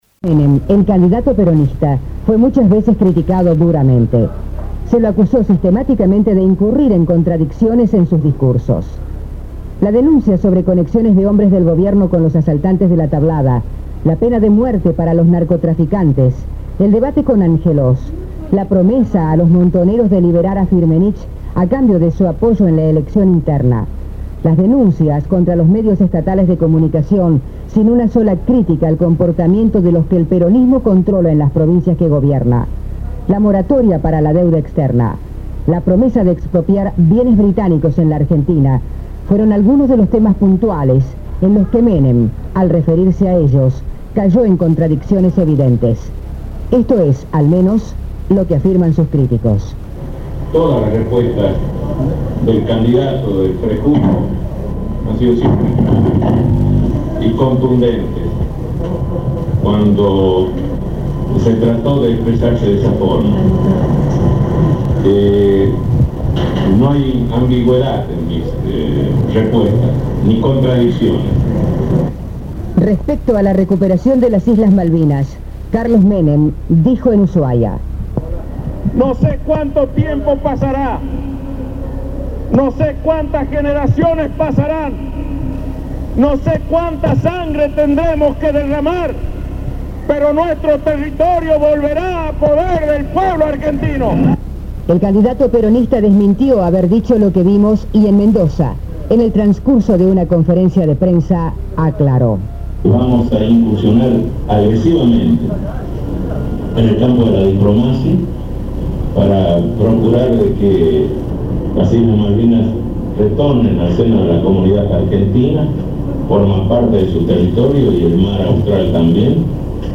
El servicio informativo de Radio Universidad produce este resumen político del año 1989, momento del primer recambio presidencial luego de la recuperación democrática.
En este resumen se escuchan las declaraciones y contradicciones de Menem sobre temas como el reclamo de soberanía sobre las Islas Malvinas, el indulto a dirigentes montoneros condenados por la justicia, la instauración de la pena de muerte, la negociación de la duda externa, entre otros.
El presidente electo anticipa definiciones sobre el gobierno que iniciaba en una entrevista televisiva realizada por los periodistas Bernardo Neustad y Mariano Grondona.